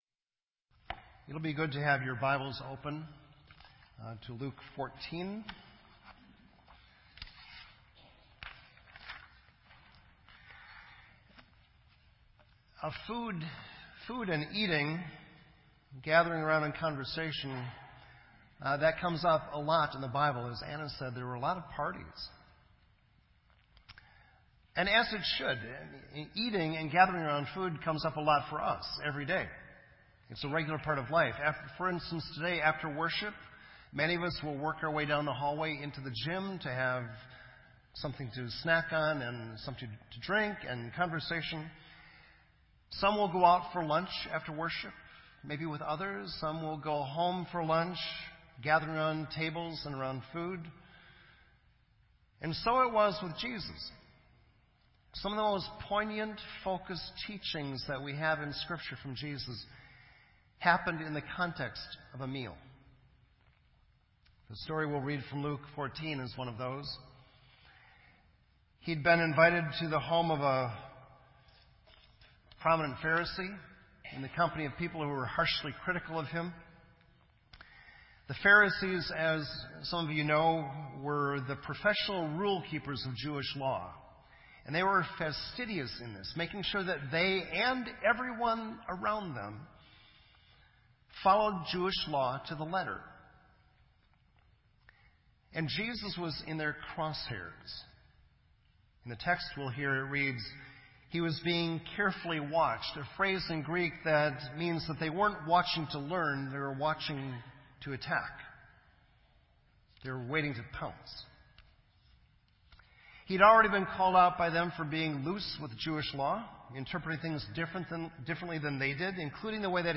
sermon
This entry was posted in Sermon Audio on October 16